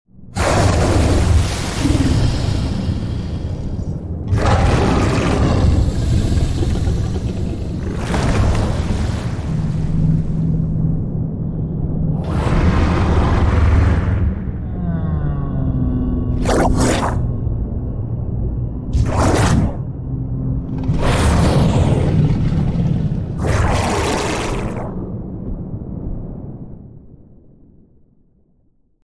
Underwater Dark Creature Sound FX